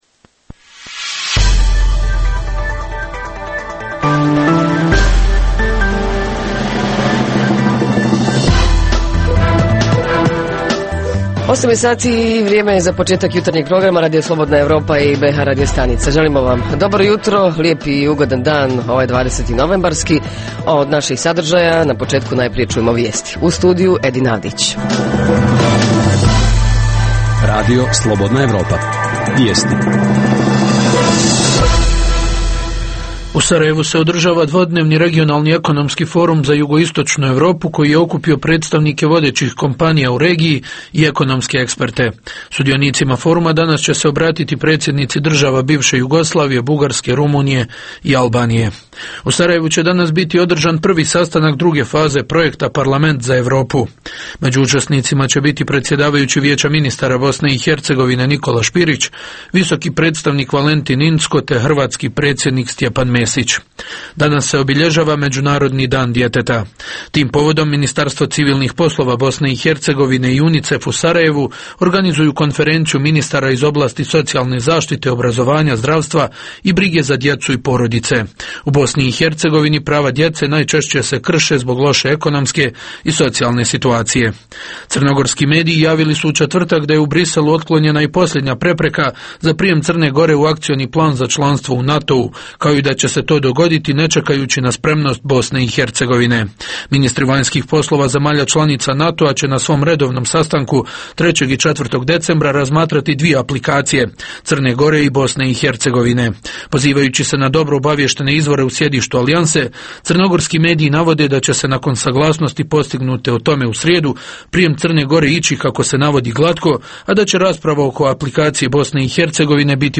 Jutarnji program ovog jutra ima za temu: razvoj mljekarske industrije i proizvodnje Reporteri iz cijele BiH javljaju o najaktuelnijim događajima u njihovim sredinama.
Redovni sadržaji jutarnjeg programa za BiH su i vijesti i muzika.